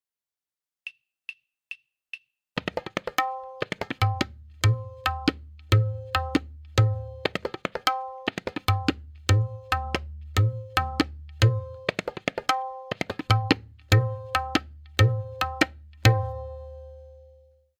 M4.2-SimpleCK-V2-Click.mp3